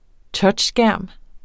Udtale [ ˈtʌdɕ- ]